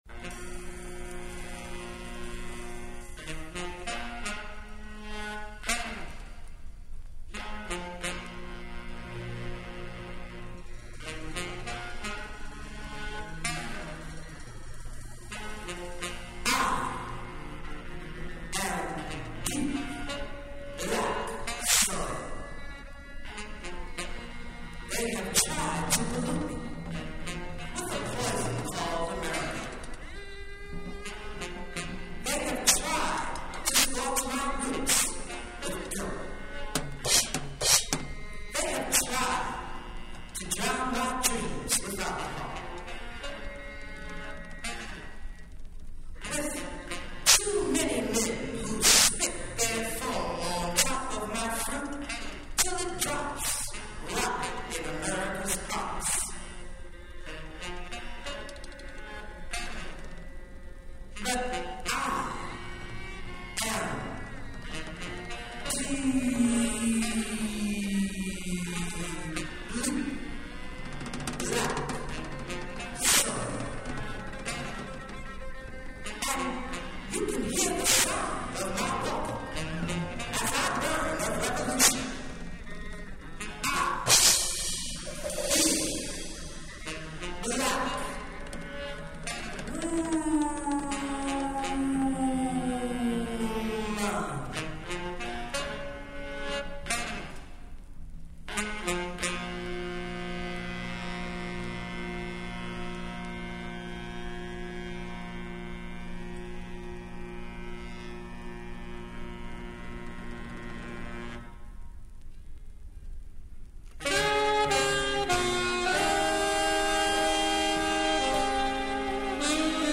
Deep and beautiful.